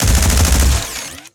GUNAuto_Sci Fi Shotgun Burst_03.wav